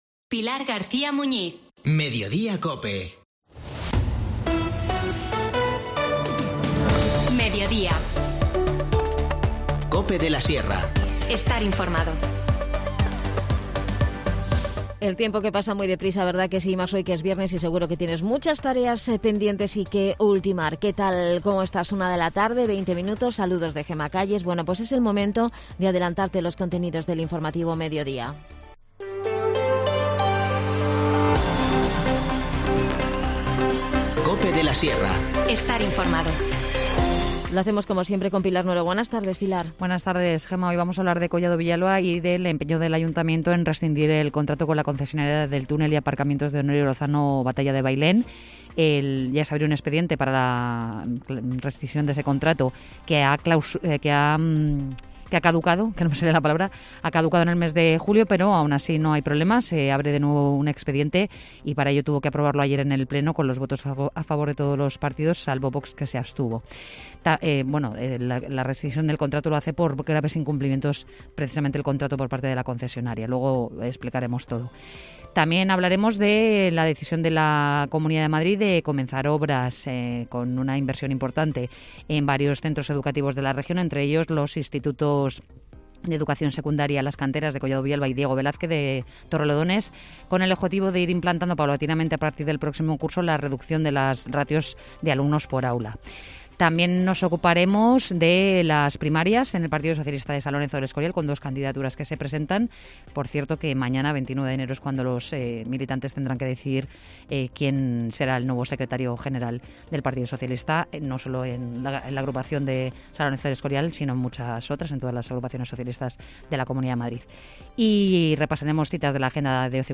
Te contamos las últimas noticias de la Sierra de Guadarrama con los mejores reportajes y los que más te interesan y las mejores entrevistas, siempre pensando en el oyente.